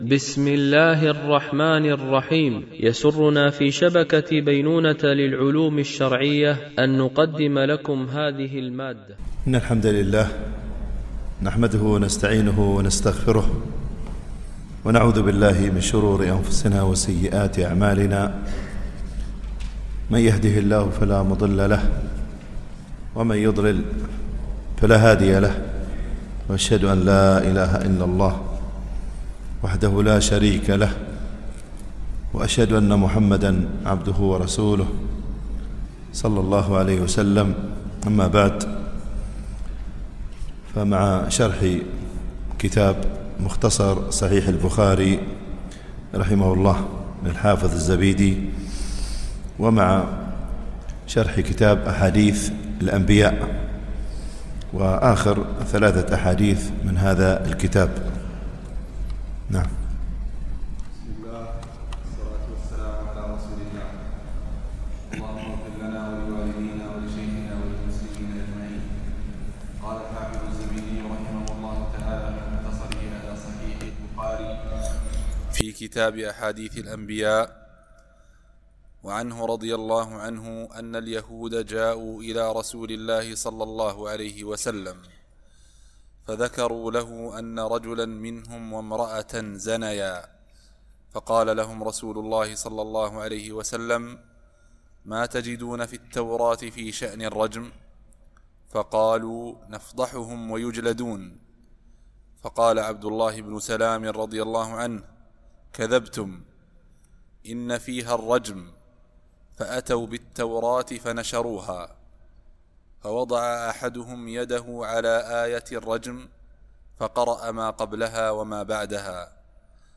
شرح مختصر صحيح البخاري ـ الدرس 248 ( كتاب أحاديث الأنبياء - الجزء الثامن والعشرون - الحديث 1517 - 1519 )